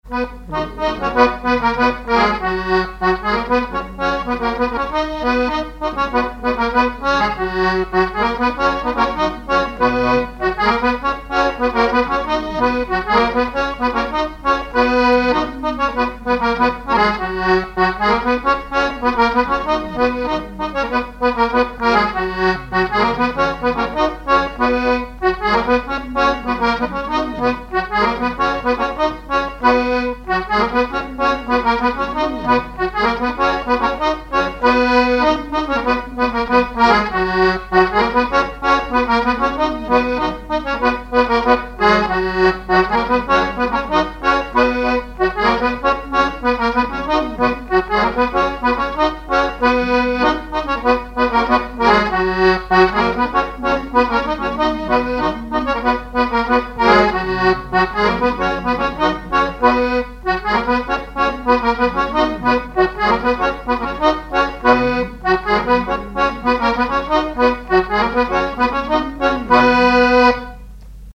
Saint-Hilaire-de-Riez
scottich trois pas
accordéon diatonique
Pièce musicale inédite